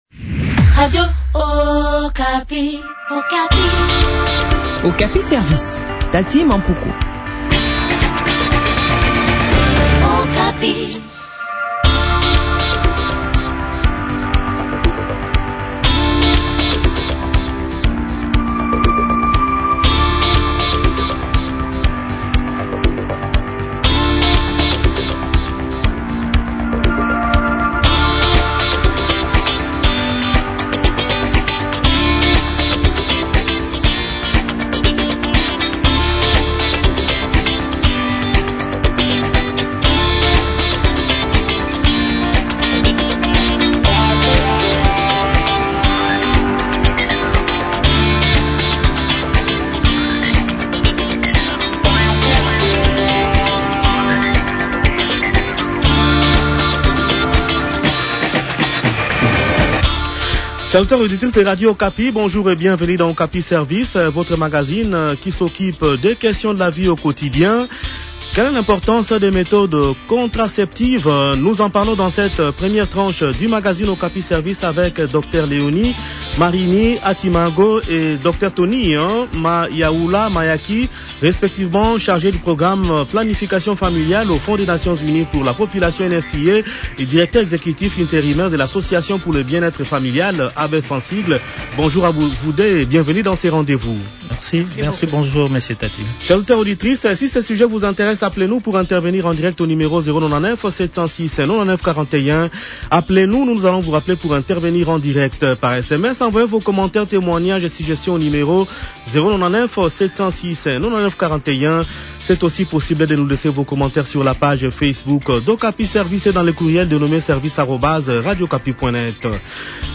s’entretient sur ce sujet